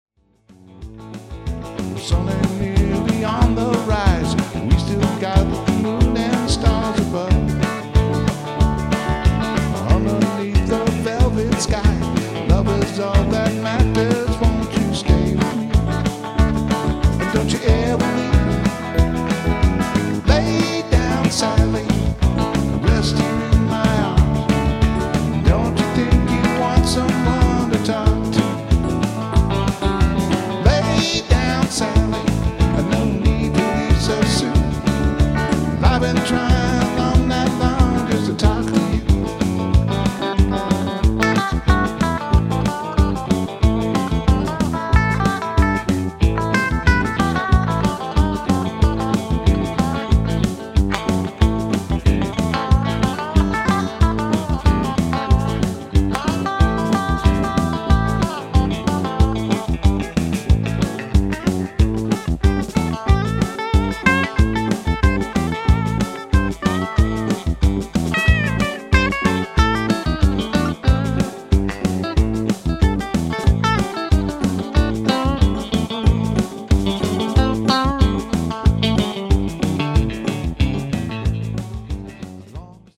Country